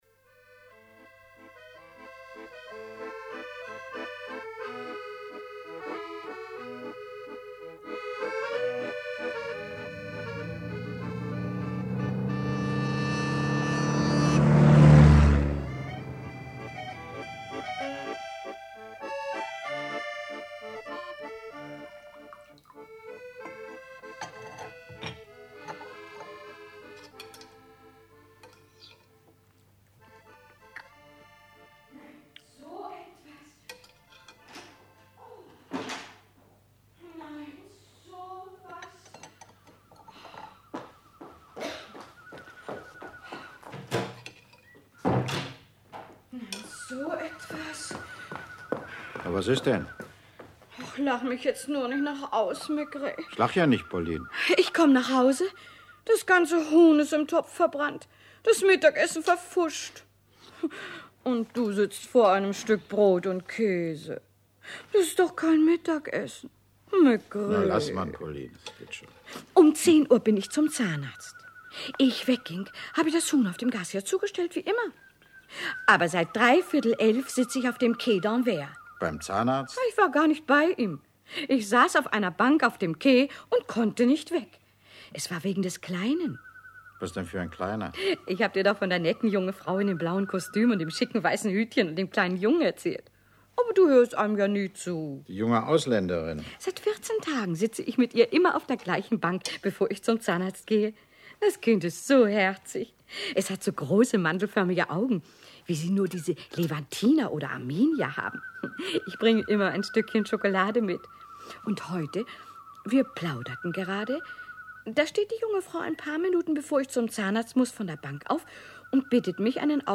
Maigret – die raffiniertesten Fälle Hörspiele
Leonard Steckel , Peter Lühr , Cordula Trantow (Sprecher)